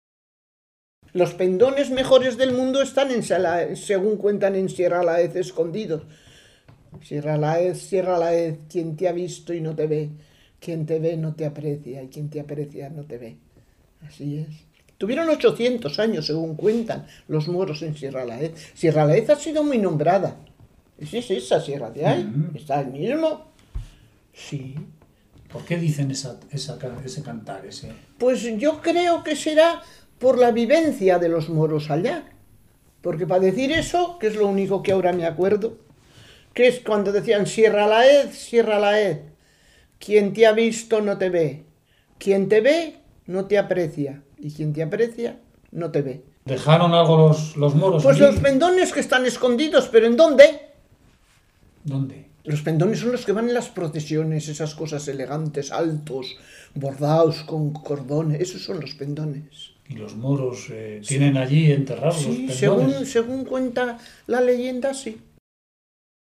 Lugar y fecha de recogida: Logroño, 14 de octubre de 2003